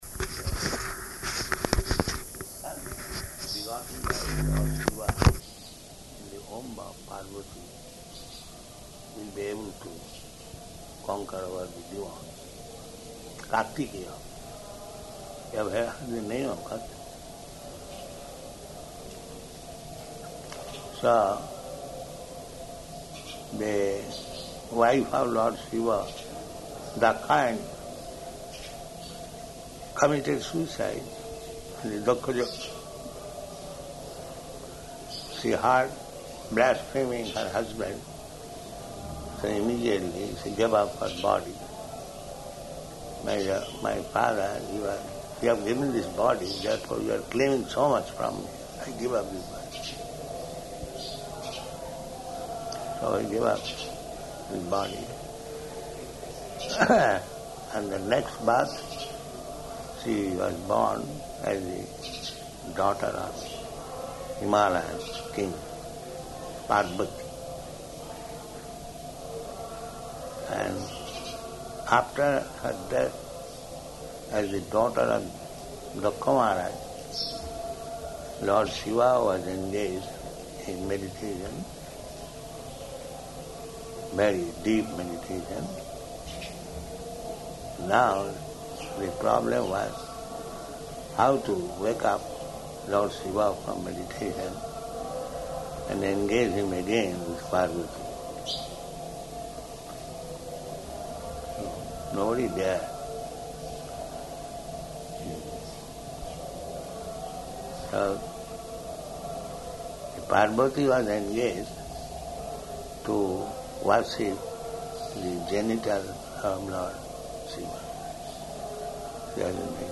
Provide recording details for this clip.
Conversation on Roof Location: Māyāpur